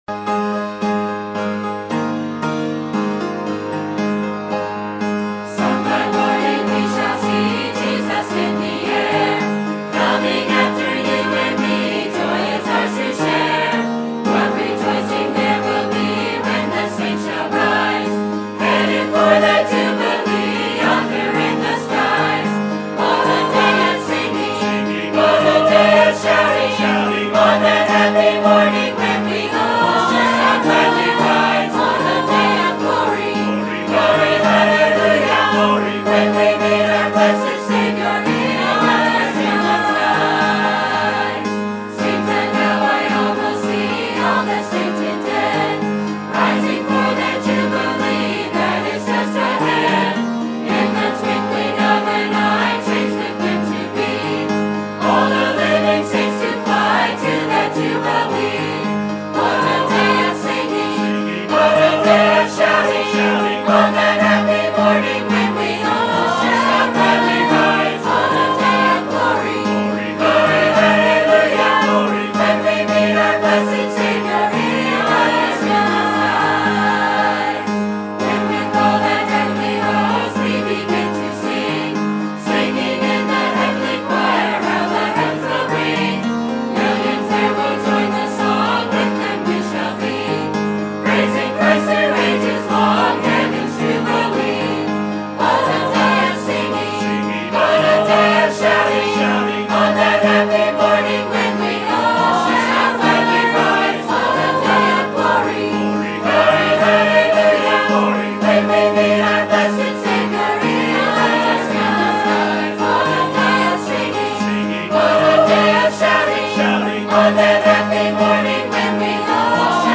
Sermon Topic: Spring Revival Sermon Type: Special Sermon Audio: Sermon download: Download (29.77 MB) Sermon Tags: Exodus Revival Holiness Accountability